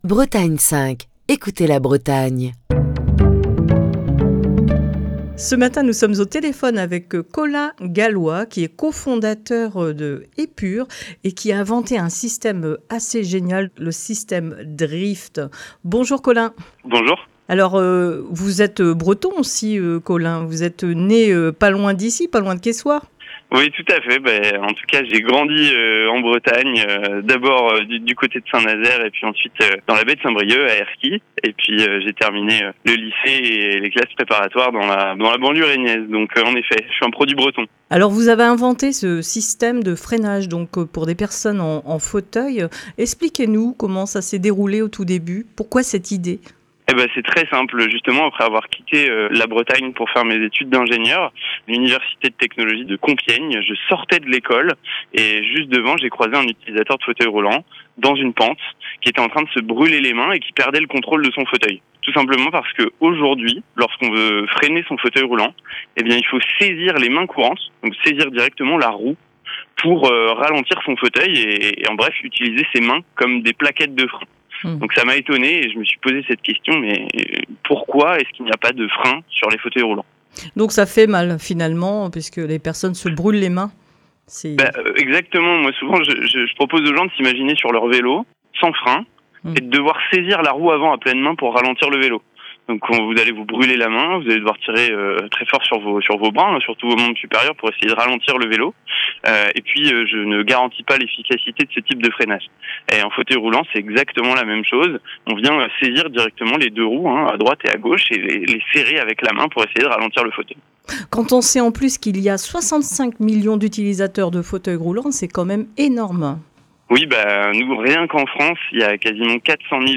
passe un coup de fil